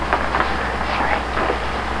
EVP 7: In here